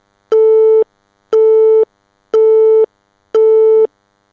phone_hangup.mp3